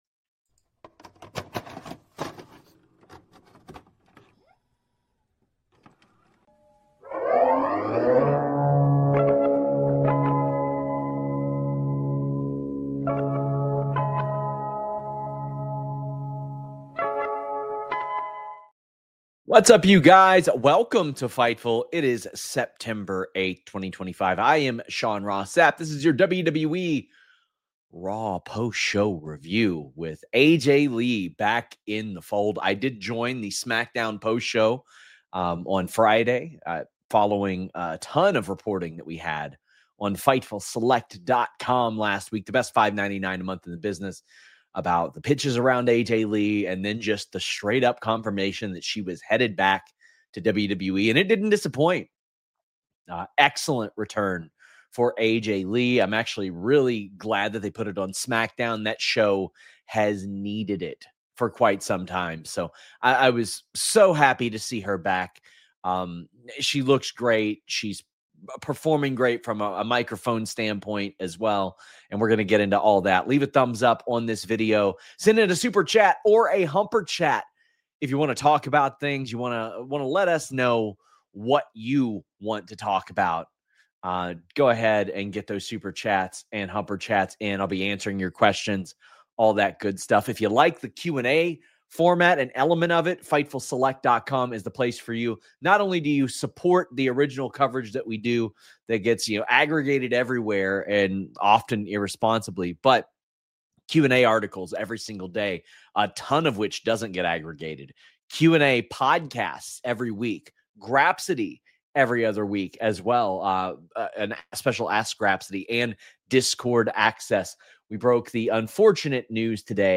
Download - Chelsea Green On WWE Release, Injuries, Vince McMahon Meeting | 2021 Shoot Interview | Podbean